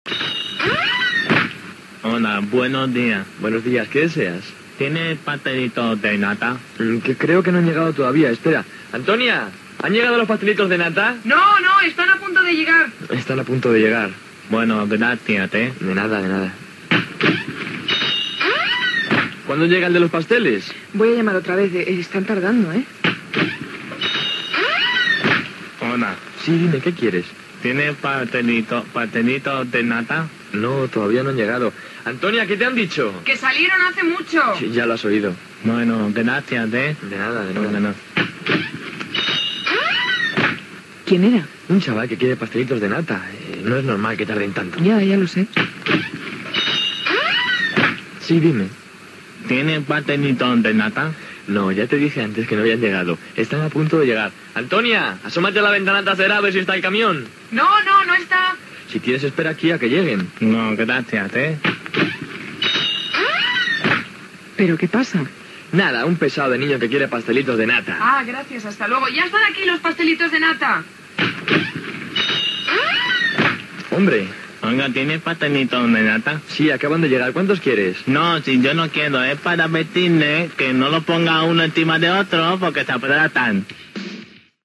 "Sketch" humorístic ¿Tiene pastelitos de nata?
Entreteniment